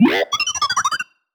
sci-fi_driod_robot_emote_18.wav